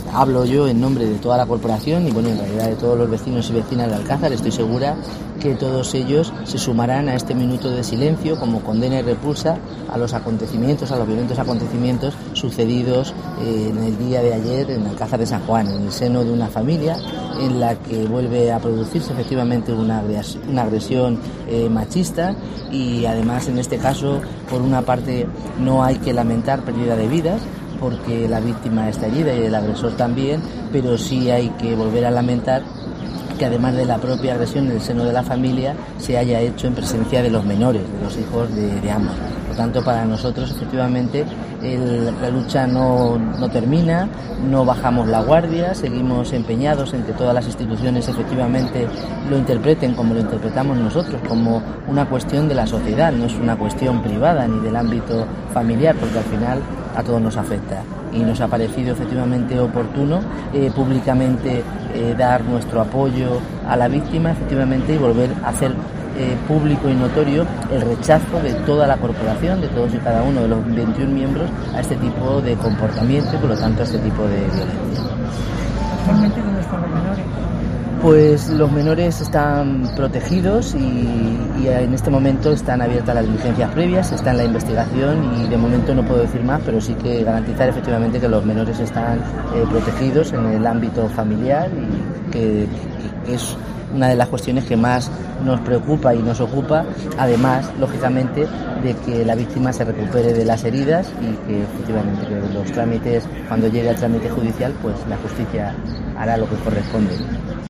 Rosa Melchor, alcaldesa de Alcázar de San Juan, en la concentración en repulsa del caso de violencia machista